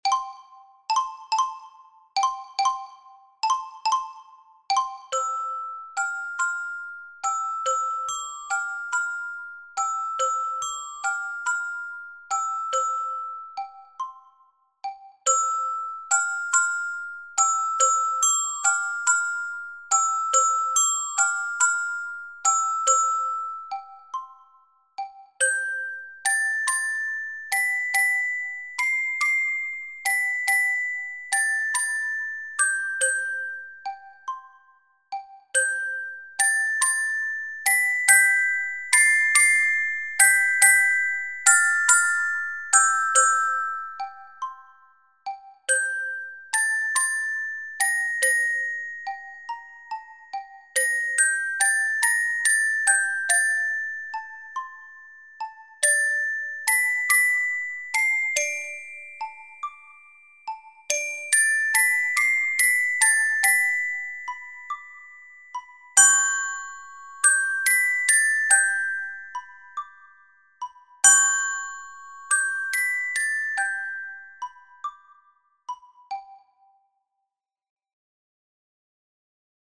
This is a melody to sing.